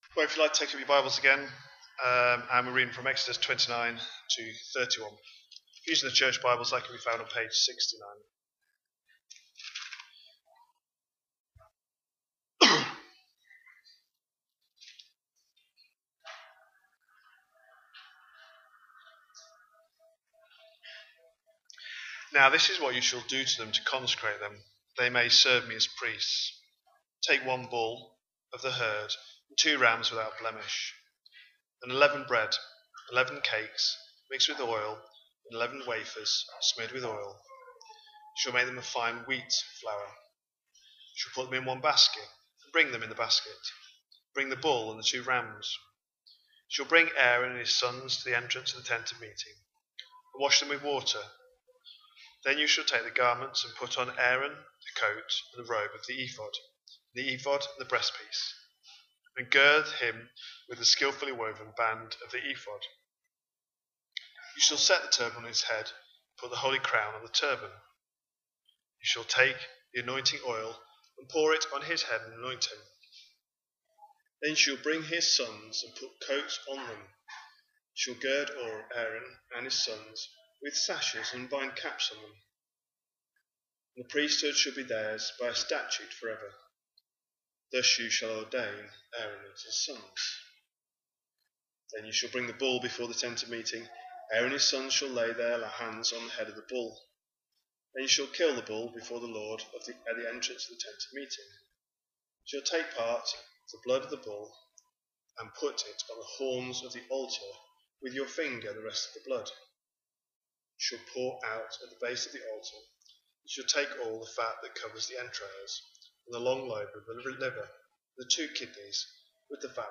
A sermon preached on 14th September, 2025, as part of our Exodus series.